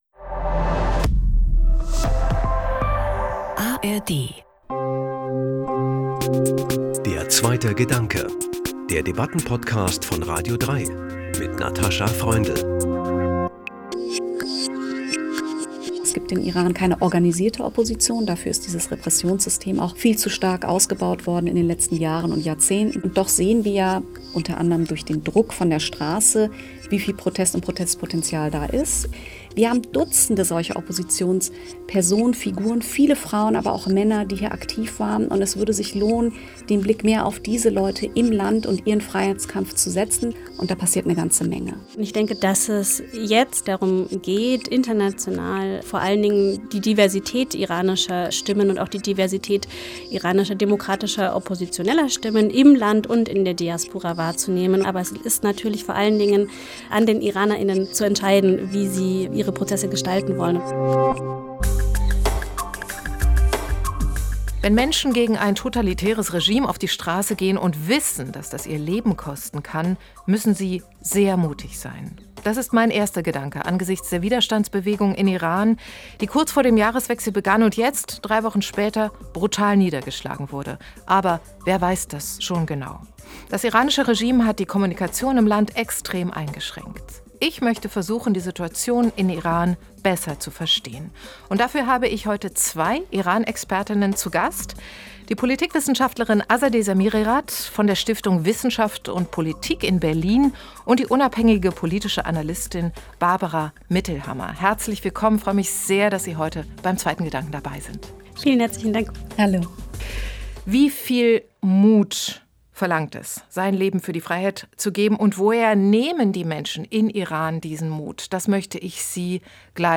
Die Debatte